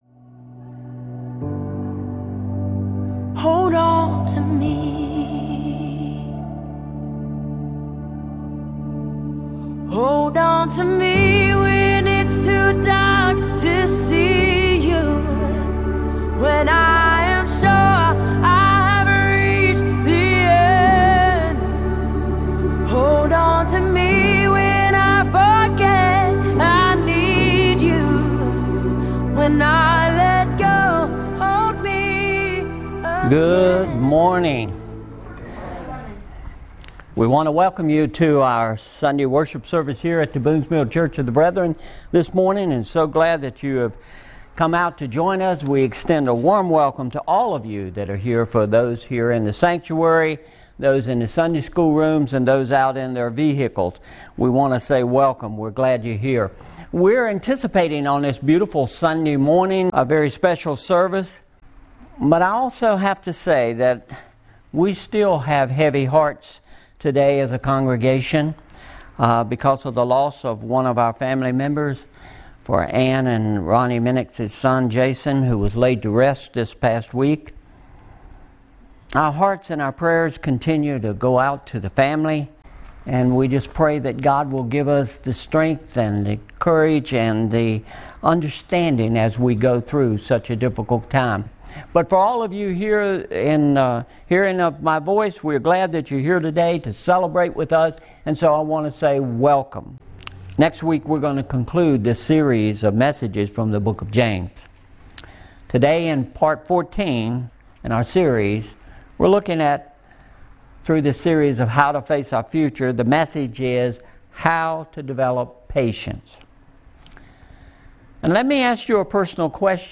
Apr 25, 2021 How to Develop Patience MP3 Notes Discussion Sermons in this Series Sermon Series How to Face Our Future!